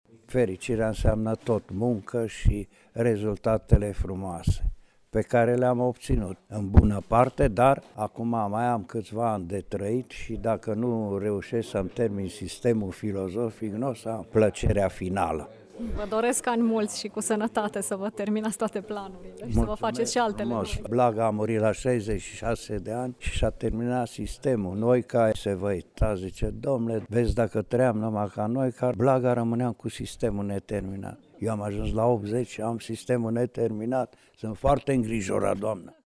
A XVIII-a ediție a Festivalului Internațional ,,Lucian Blaga”, organizat de Societatea Scriitorilor Mureșeni și Editura Ardealul, a debutat, vineri, la Radio România Tg.Mureș.